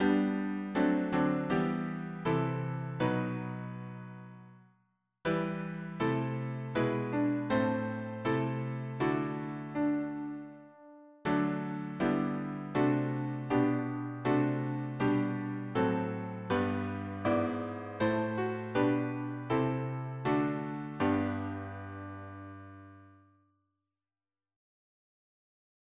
Information about the hymn tune DOMINICA.